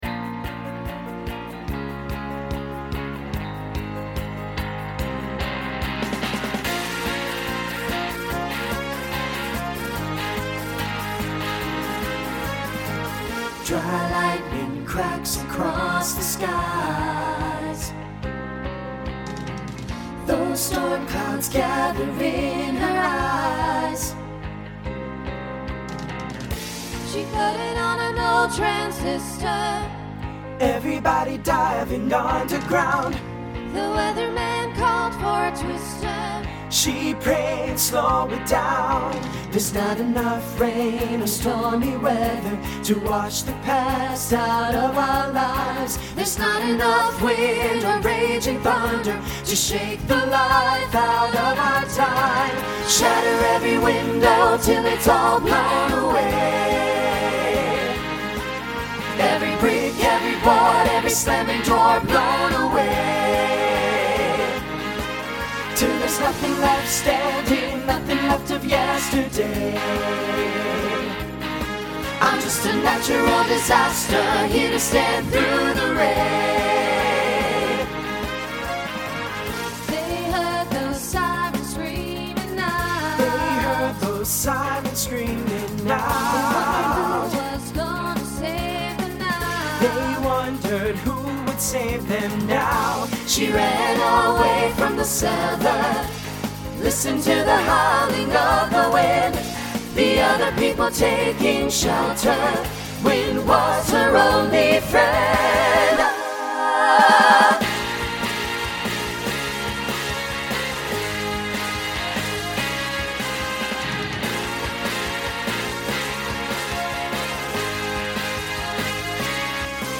Voicing SATB Instrumental combo Genre Country , Rock